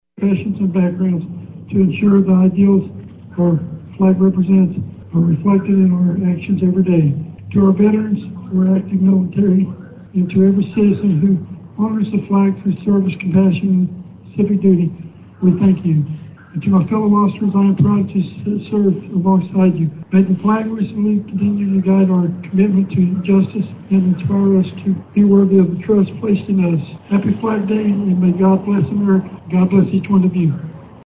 remarks from Bartlesville Police Chief Kevin Iklelberry on the flag’s significance to law enforcement.
FLAGDAY    Event   Cheif Ikleberry.mp3